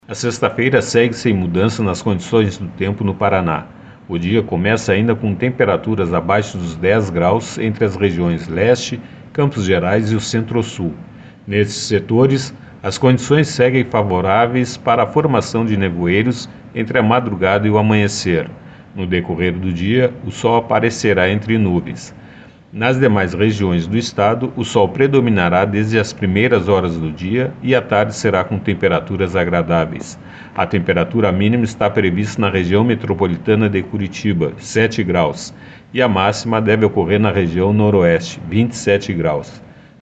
Ouça o que diz o meteorologista do Simepar